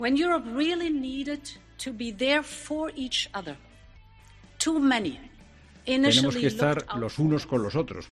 Úrsula Von der Leyen, ayer en el Consejo Europeo